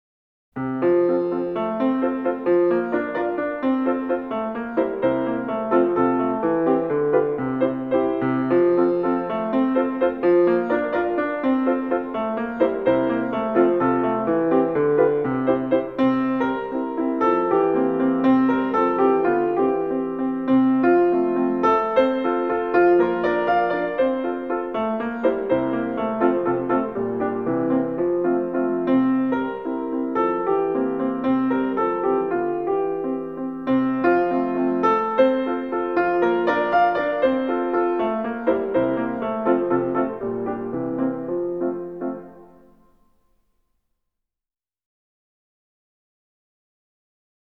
世界名曲钢琴小品